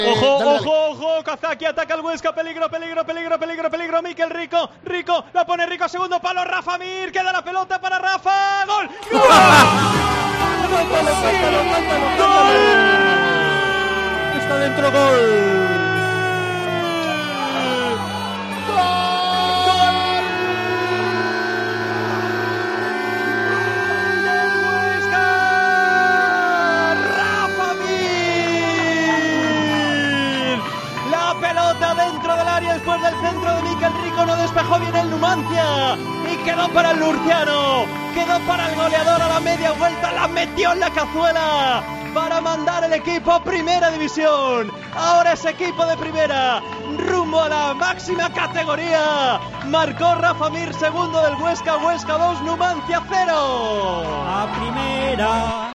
Narración Gol de Rafa Mir / 2-0